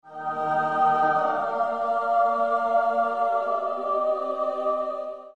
На этой странице собраны умиротворяющие звуки Бога и ангелов — небесные хоры, божественные мелодии и атмосферные треки для медитации, релаксации или творческих проектов.
Звук при явлении святого духа или священника